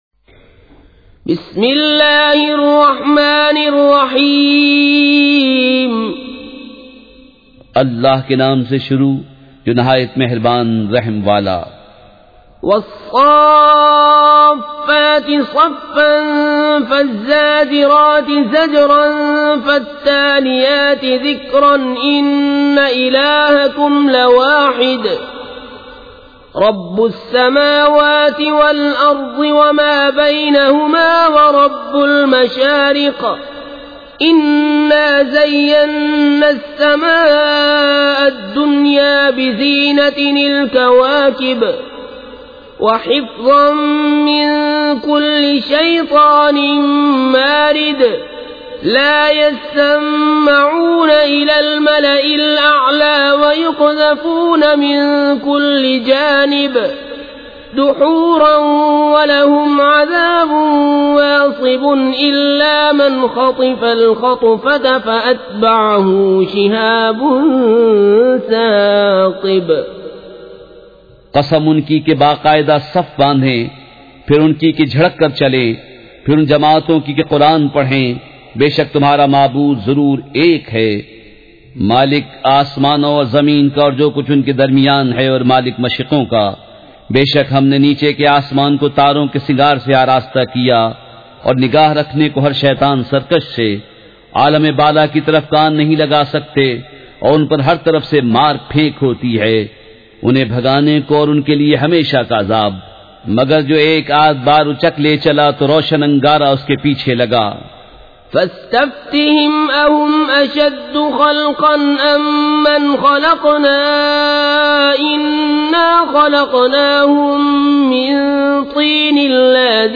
سورۃ الصافات مع ترجمہ کنزالایمان ZiaeTaiba Audio میڈیا کی معلومات نام سورۃ الصافات مع ترجمہ کنزالایمان موضوع تلاوت آواز دیگر زبان عربی کل نتائج 1643 قسم آڈیو ڈاؤن لوڈ MP 3 ڈاؤن لوڈ MP 4 متعلقہ تجویزوآراء